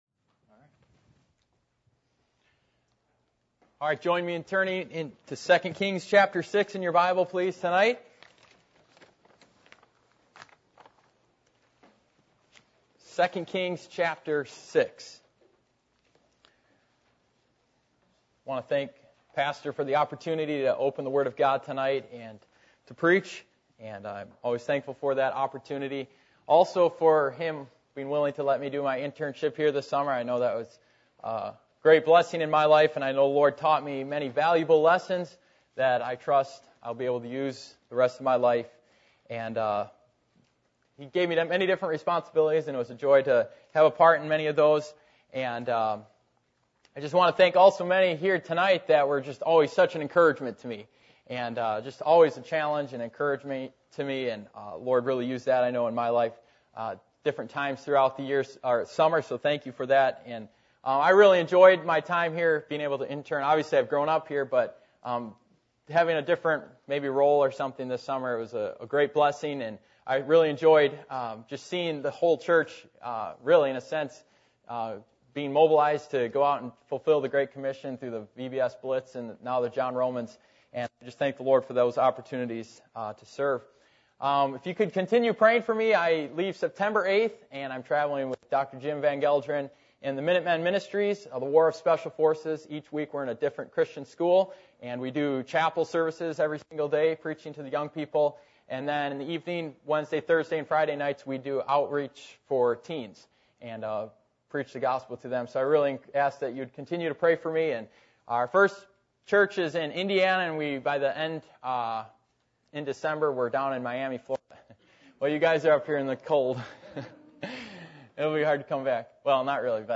Passage: 2 Kings 6:8, Hebrews 11:6 Service Type: Midweek Meeting